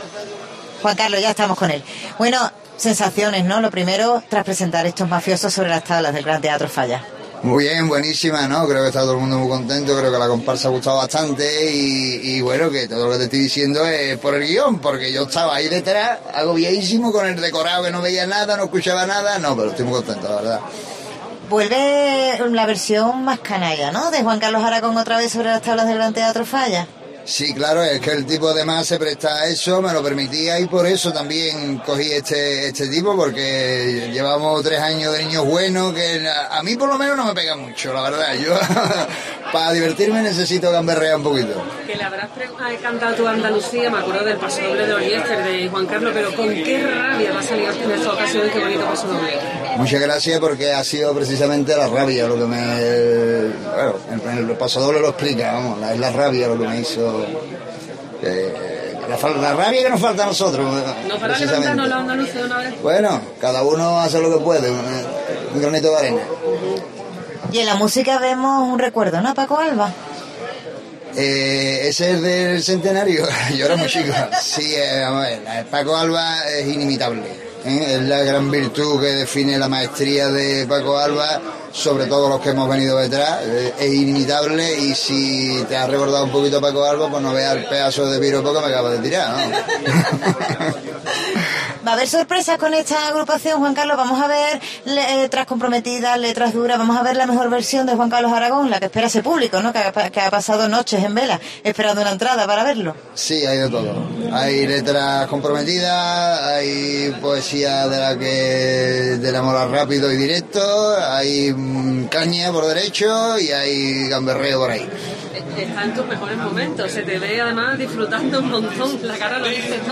AUDIO: Escucha la entrevista a Juan Carlos Aragón tras la actuación de Los Mafiosos